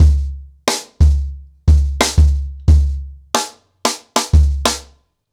CornerBoy-90BPM.7.wav